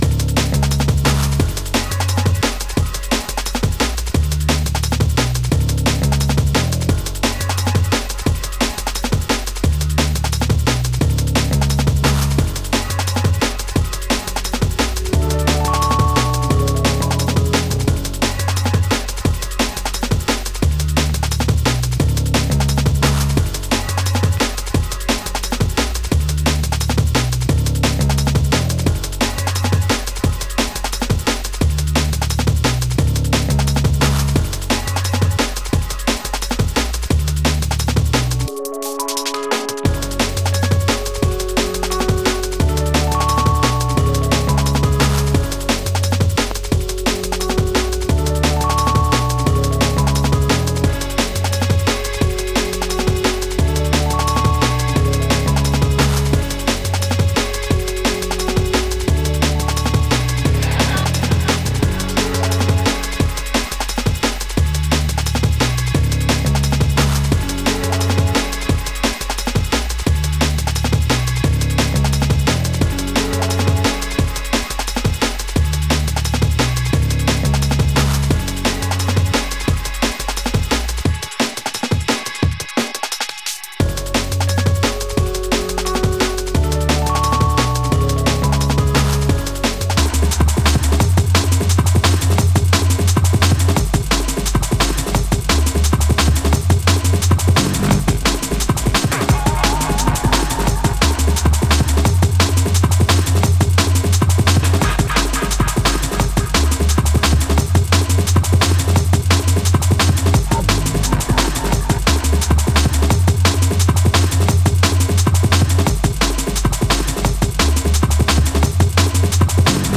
Category: Drum N Bass